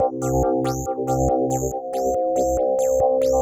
tx_synth_140_fltchops_C2.wav